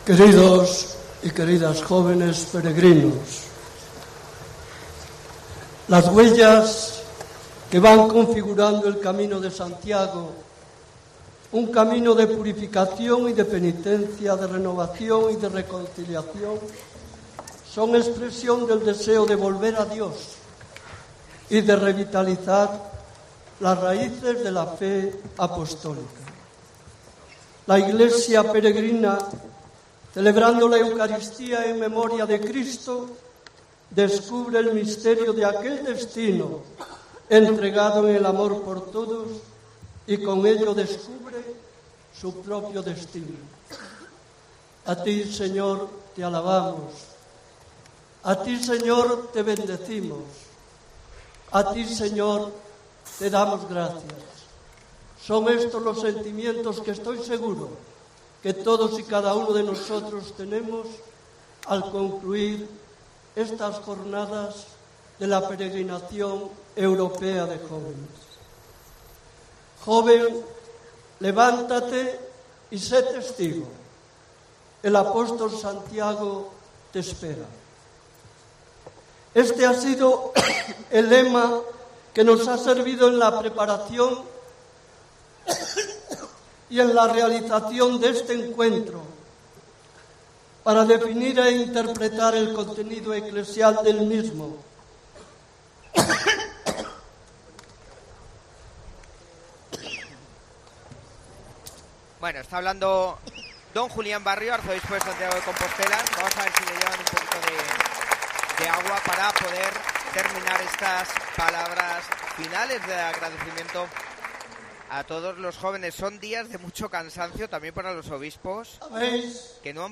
Intervención íntegra de Monseñor Barrio en la Eucaristía del Envío de la PEJ 2022